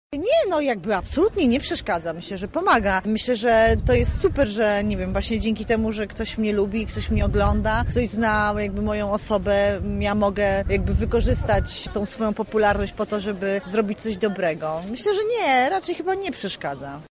Spotkanie z aktorką odbyło się na Wydziale Politologii Uniwersytetu Marii Curie-Skłodowskiej.
Na pytanie, czy rozpoznawalność przeszkadza czy też pomaga w niesieniu dobra, mówi sama Anita Sokołowska: